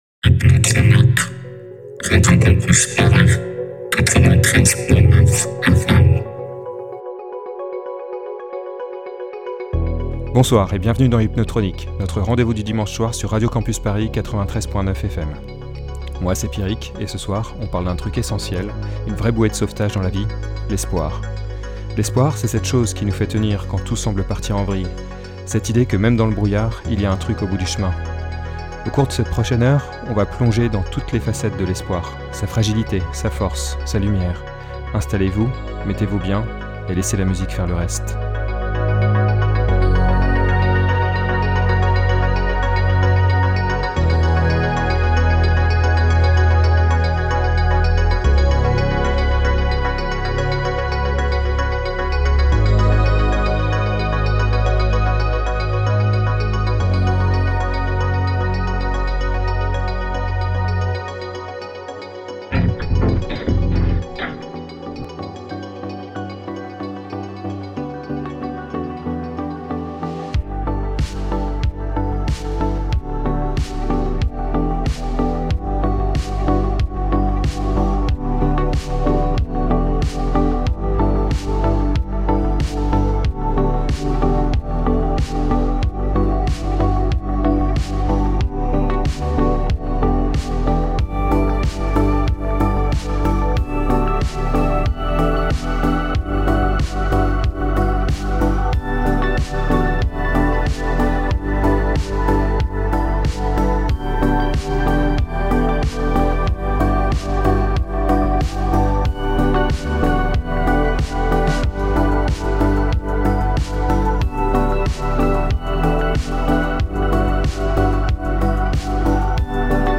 Une heure de mix pour apaiser les corps et les âmes
Type Mix Éclectique